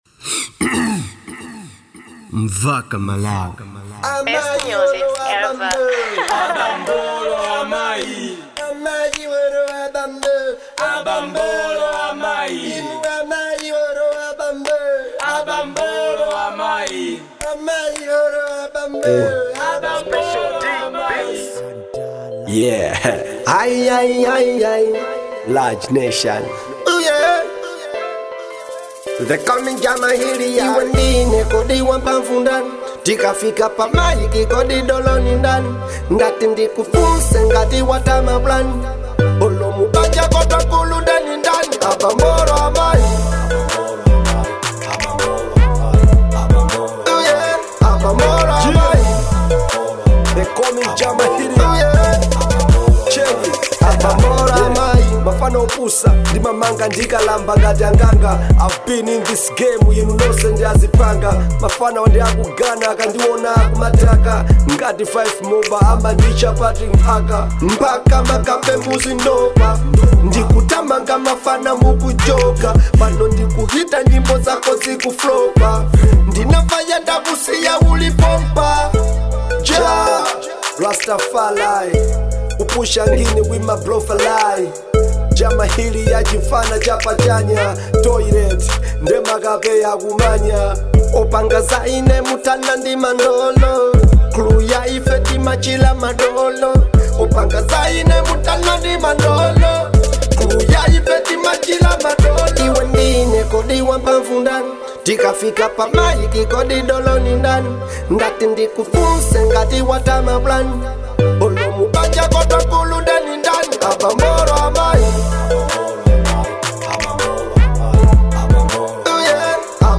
type:Dancehall